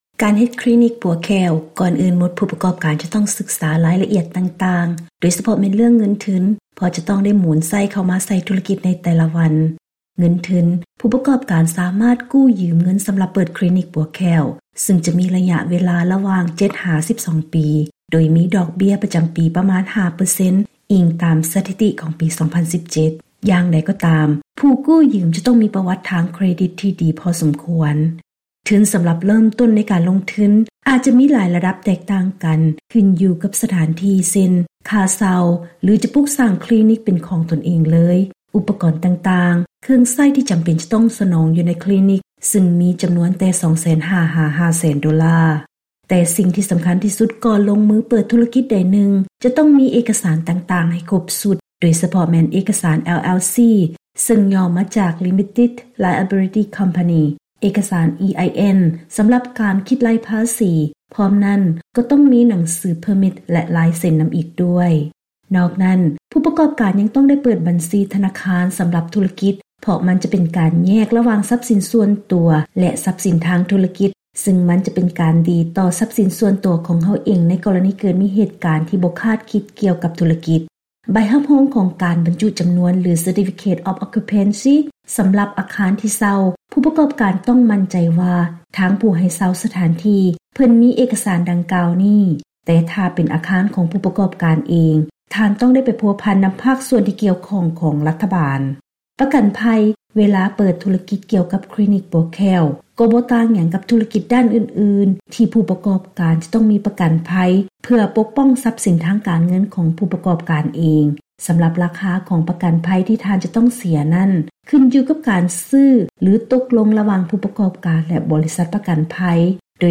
ເຊີນຮັບຟັງລາຍງານກ່ຽວກັບ ການເຮັດທຸລະກິດເປີດຄລີນິກປົວແຂ້ວ